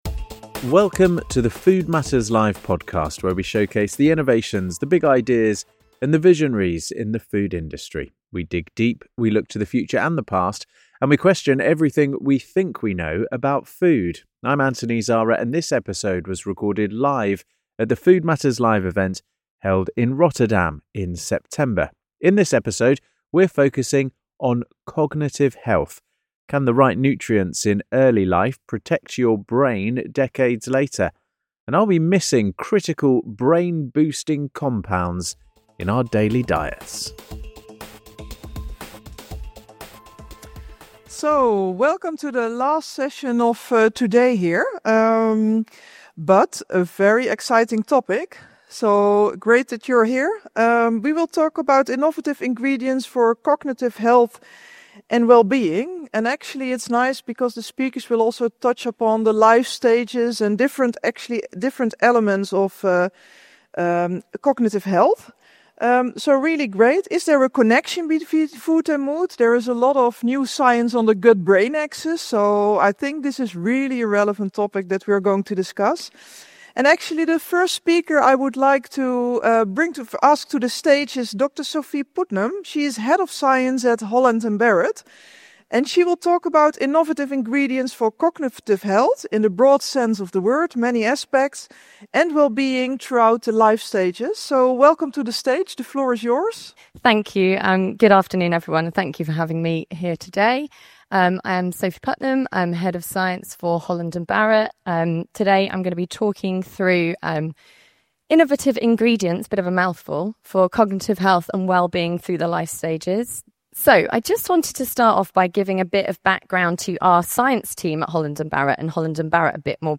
In this episode of the Food Matters Live podcast, recorded at our event in Rotterdam, neuroscience experts reveal the nutrient gaps undermining cognitive health across every life stage - and the innovative ingredients that could fill them.